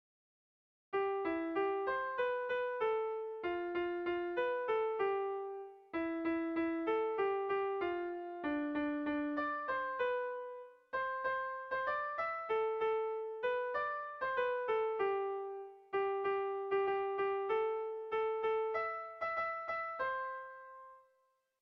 Dantzakoa
Zortziko txikia (hg) / Lau puntuko txikia (ip)
ABDE